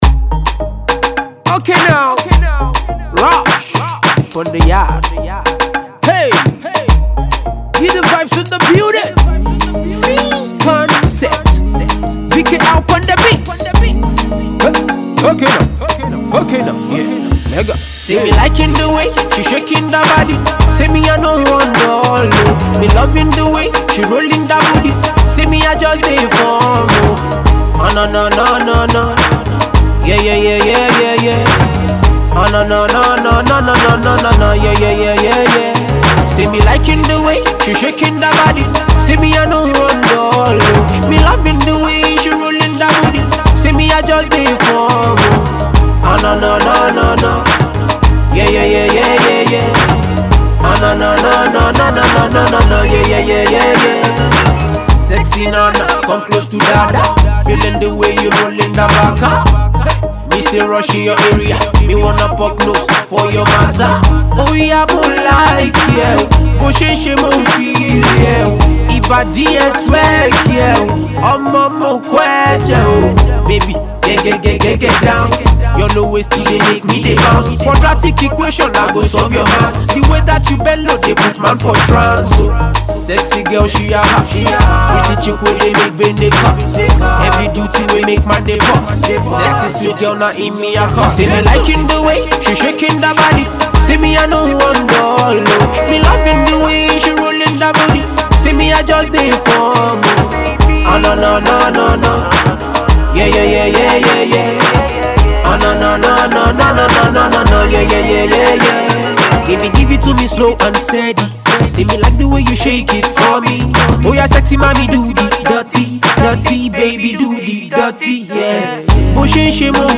pop act